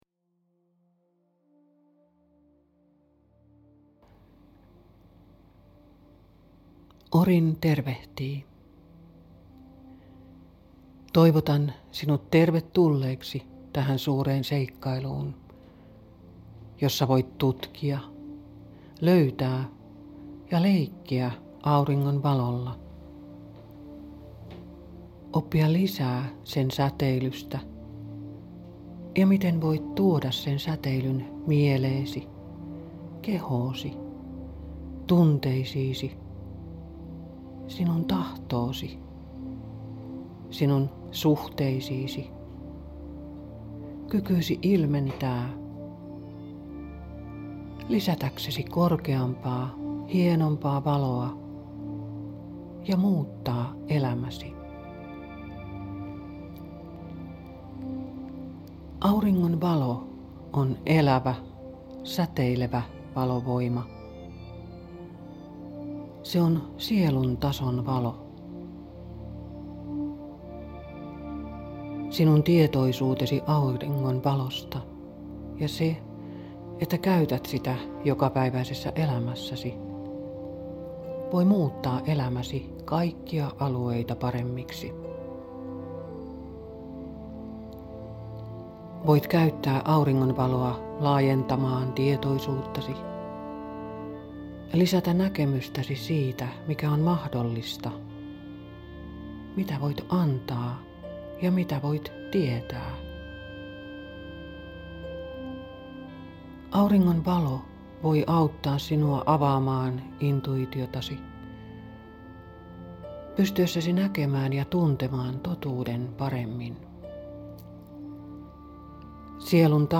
Hyödynnä ilmaista nettikurssia Solaarisen auringon säteily yhdistyessäsi tähän Valon, Harmonian ja Rakkauden -ihmisryhmään ympäri maapallon. Kuuntele ohjattu meditaatio: Solaarinen aurinko ******* Sinä nostat minut ylös *************** Kultainen Avain Elämä hymyilee minulle Minä hymyilen elämälle ************